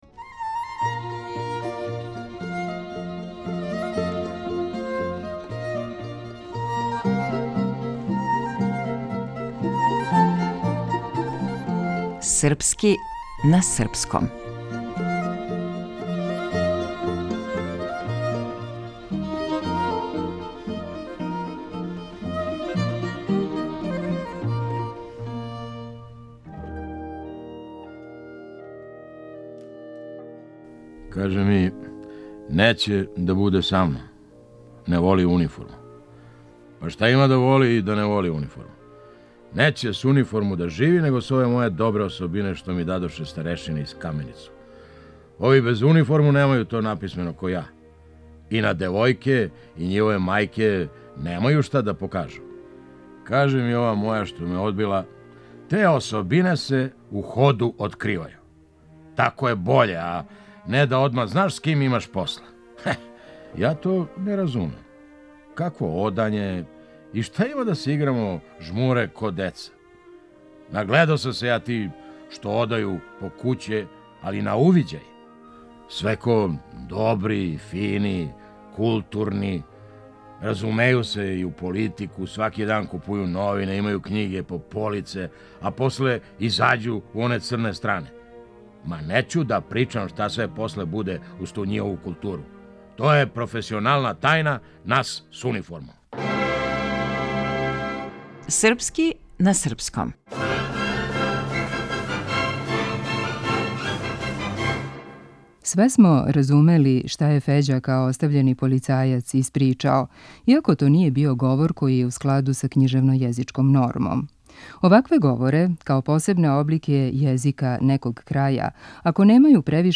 Драмски уметник - Феђа Стојановић